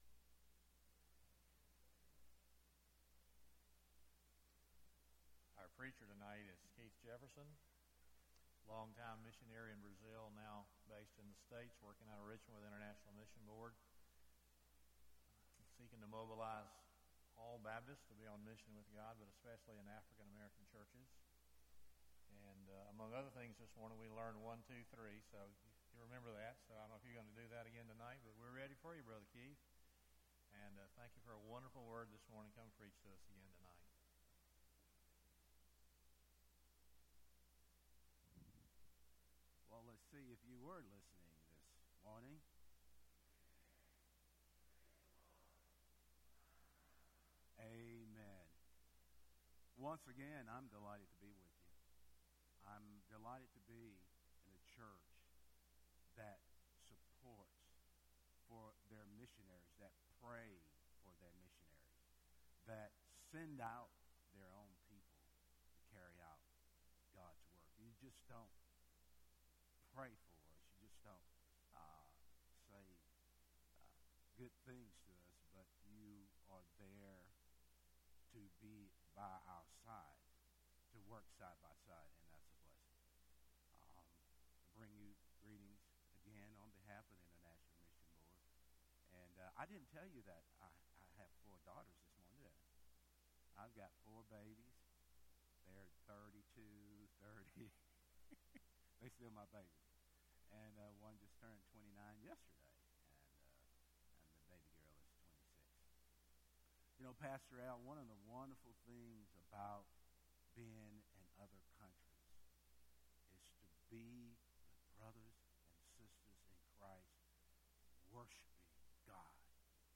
International Missions Festival Guest Speaker
Sermon